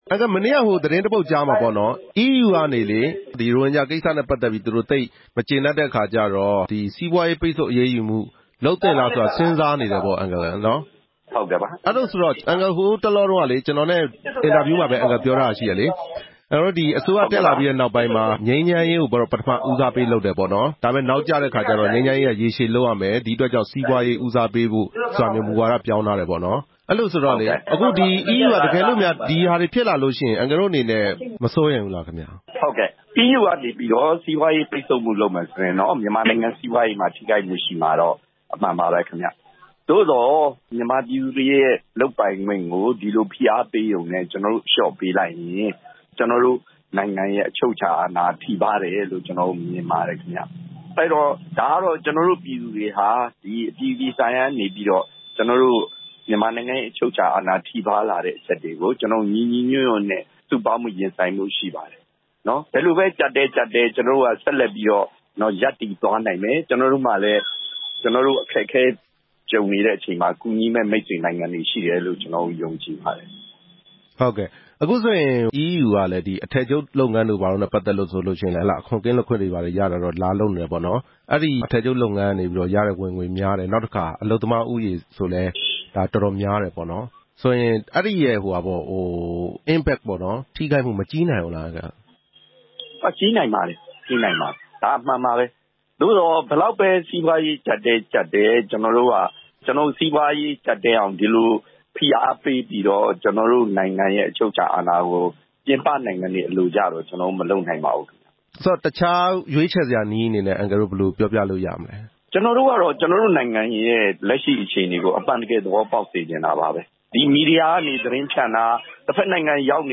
EU ပိတ်ဆို့မှုအလားအလာ ဆက်သွယ်မေးမြန်းချက် – မြန်မာဌာန